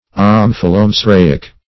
Search Result for " omphalomesaraic" : The Collaborative International Dictionary of English v.0.48: Omphalomesaraic \Om`pha*lo*mes`a*ra"ic\, a. [Omphalo- + mesaraic.]